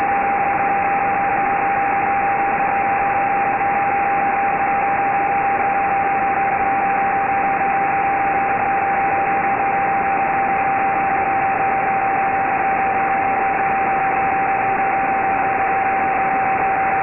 Again the signal was a strong, very strong carrier with audible modulation, but the modulation was different, a pulse train...
It seems that there is some kind of “frame” marker every 6.6 seconds.
Pulse train on 1020.5 MHz